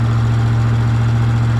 描述：发动机
Tag: 汽车 卡车 轿车 发动机